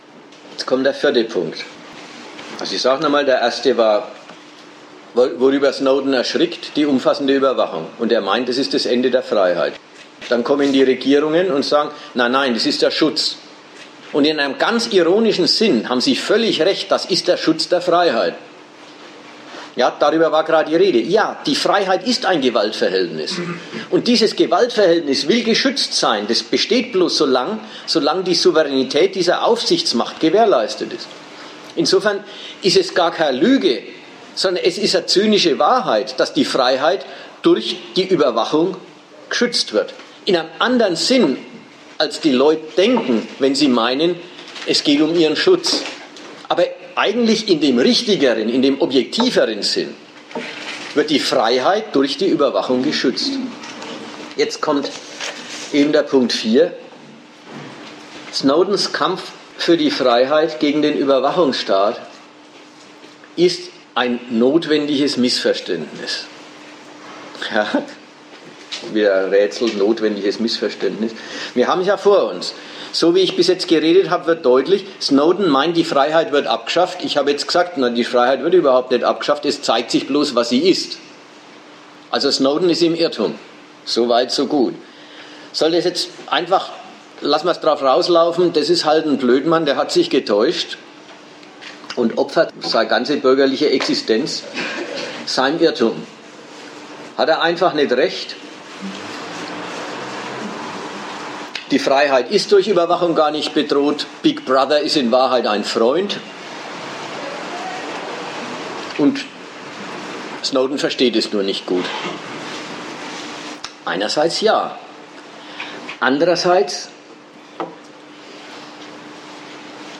Der Vortrag bei der Sozialistischen Gruppe ist hier.